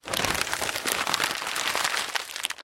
Звуки сворачивания бумаги
Печатную газету сжали в комок